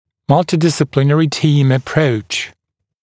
[ˌmʌltɪˌdɪsɪ’plɪnərɪ tiːm ə’prəuʧ][ˌмалтиˌдиси’плинэри ти:м э’проуч]междисциплинарный командный подход, работа команды представителей разных врачебных специальностей